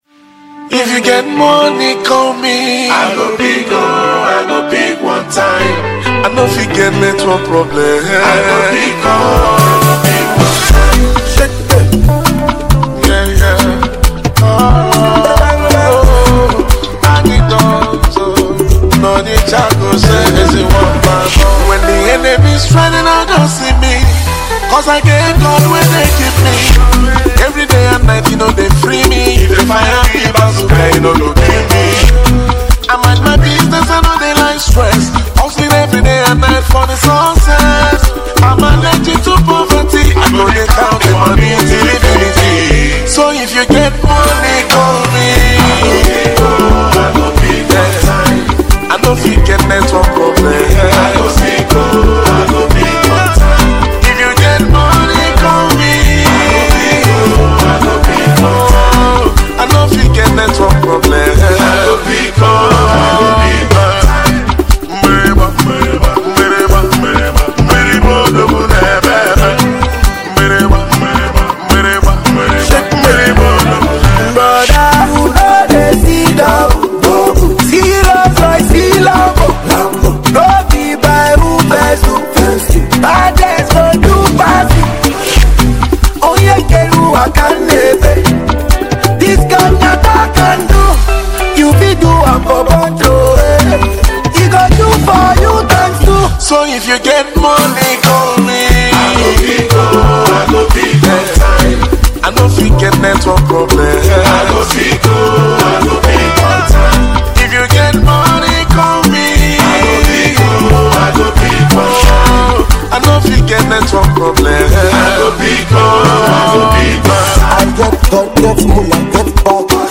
indigenous highlife singer and song composer